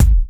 Kick _05.wav